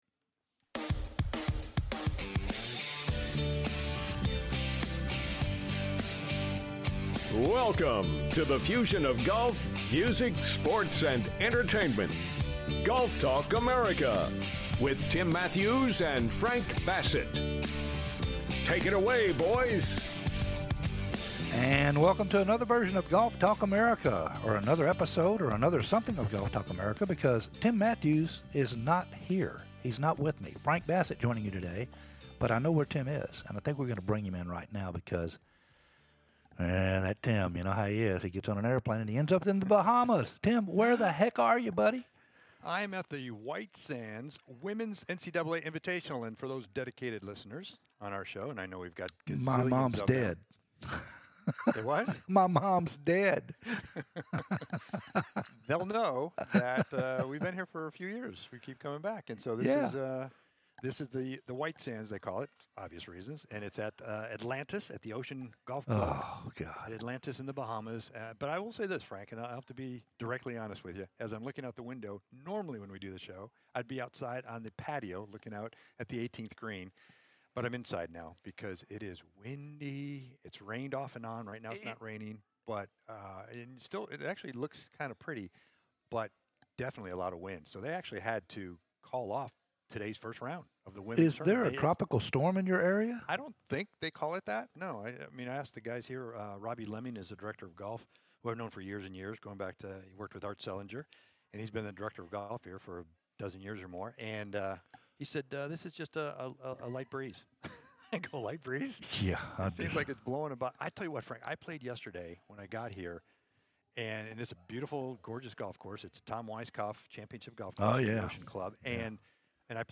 "LIVE" FROM THE BAHAMAS THE WHITE SANDS INVITATIONAL AT ATLANTIS